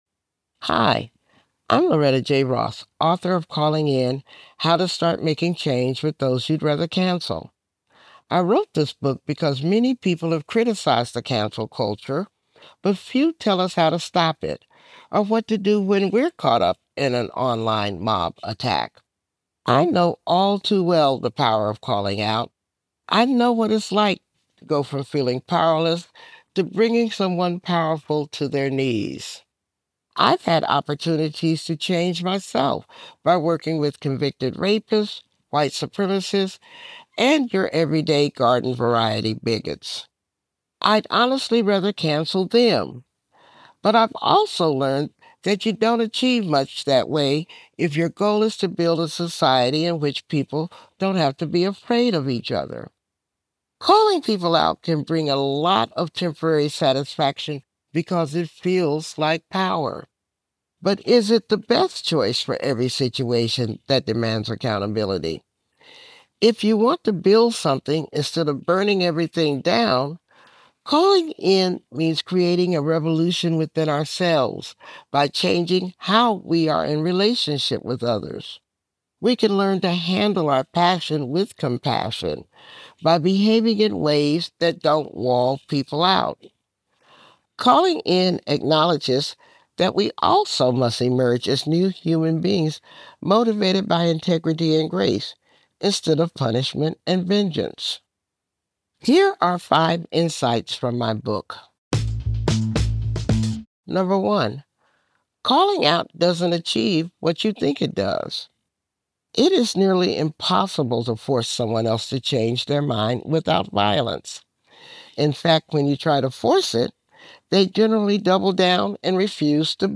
Listen to the audio version—read by Loretta herself—in the Next Big Idea App.